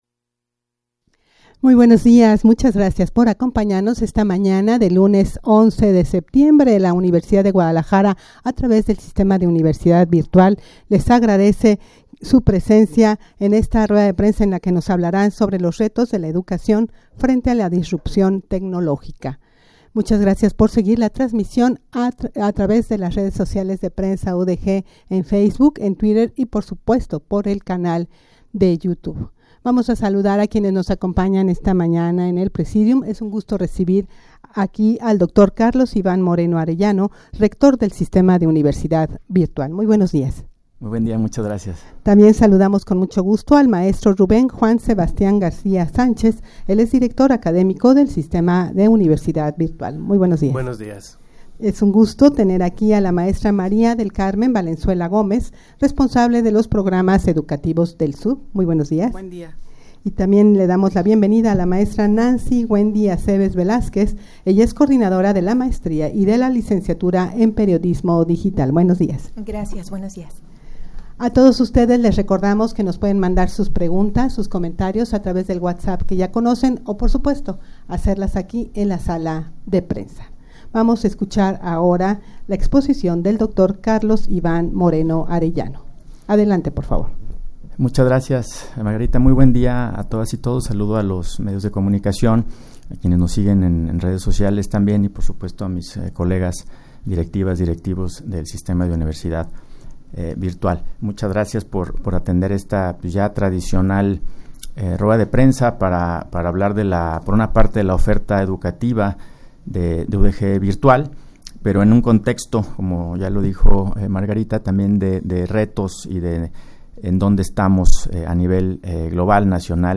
Audio de la Rueda de Prensa
rueda-de-prensa-retos-de-la-educacion-frente-a-la-disrupcion-tecnologica.mp3